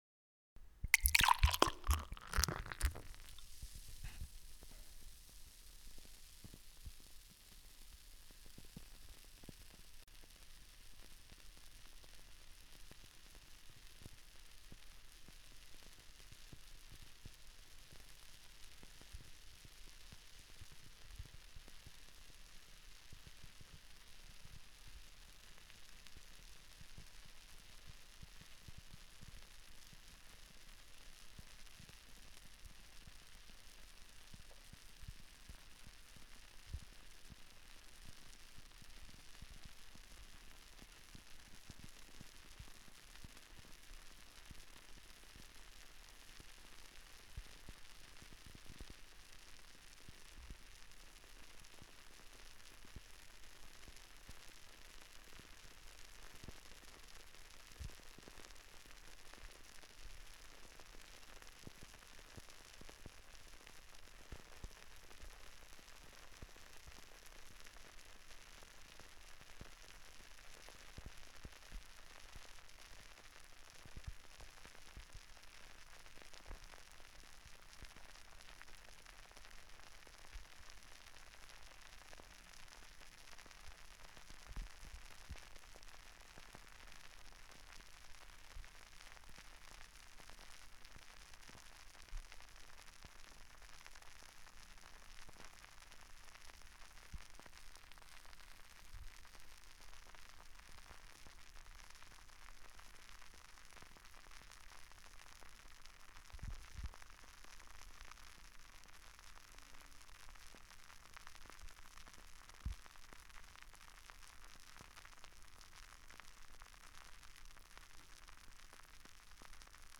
Звуки открытия пива
Звук пива наливающегося в стакан и шум пены с шипением